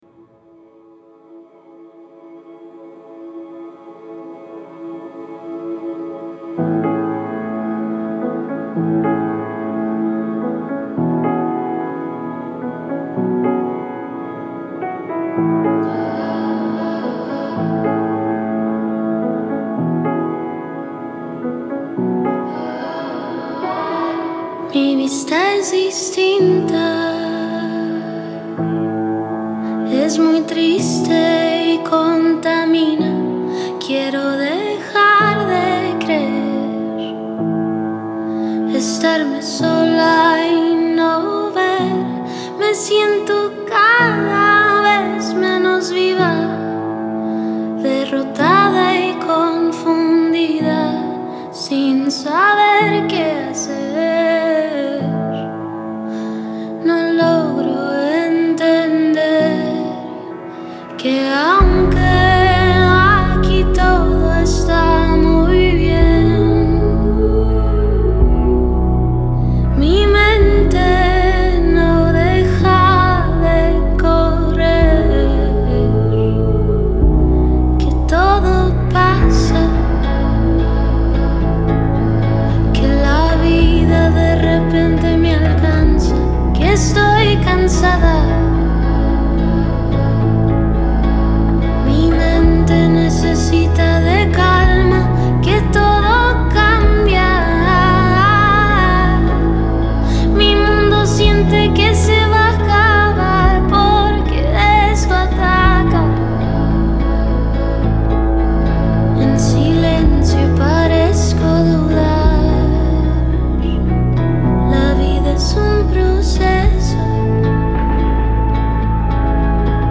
Латиноамериканская